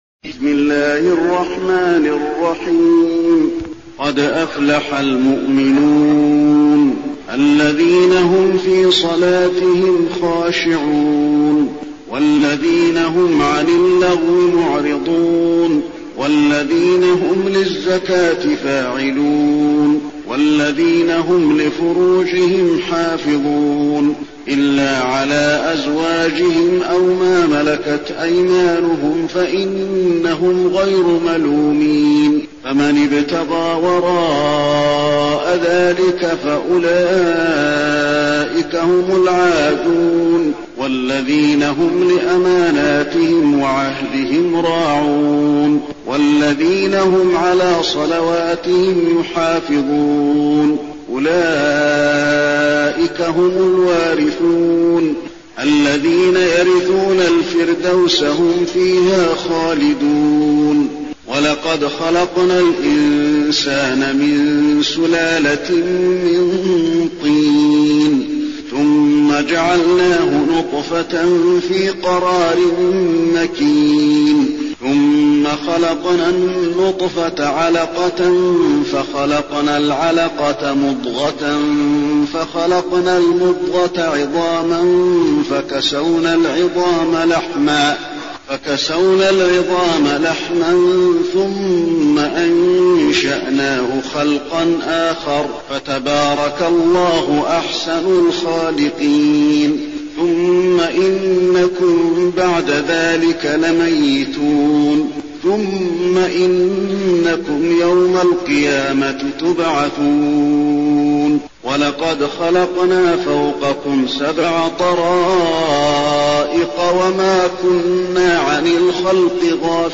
المكان: المسجد النبوي المؤمنون